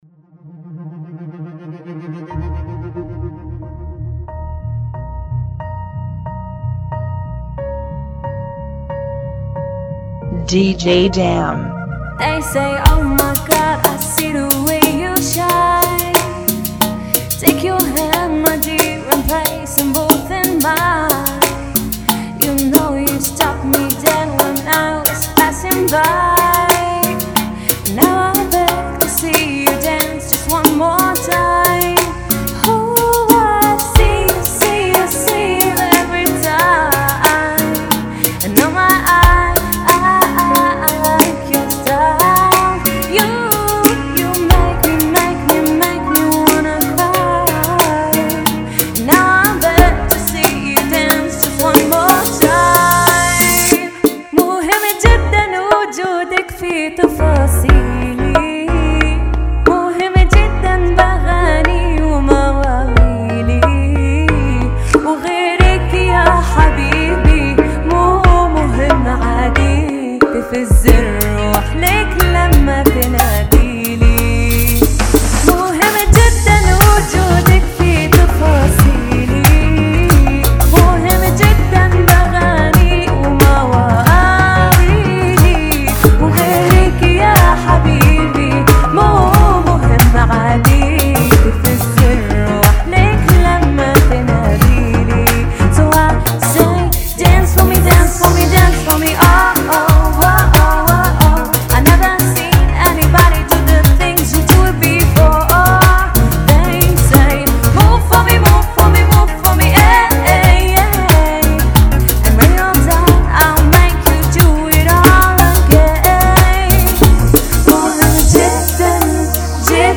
91 BPM
Genre: Bachata Remix